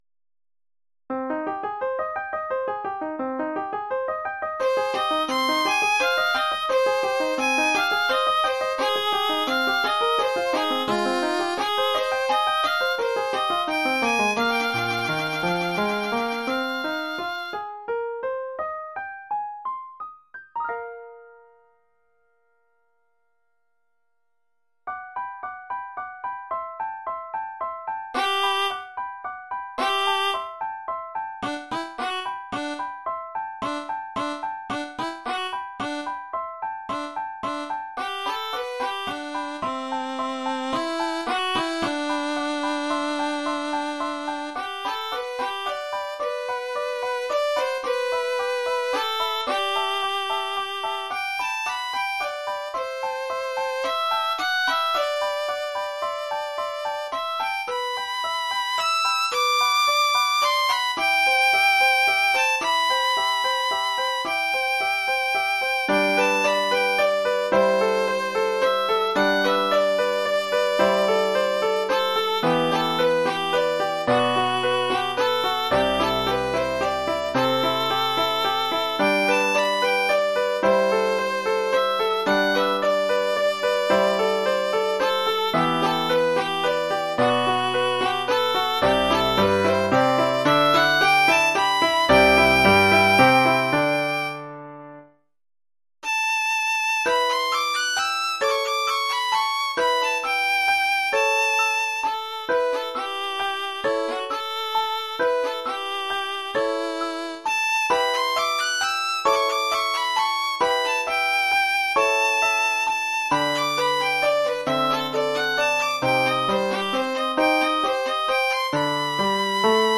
Formule instrumentale : Violon et piano
Oeuvre pour violon et piano.